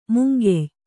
♪ mungey